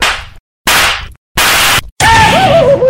Mario Slap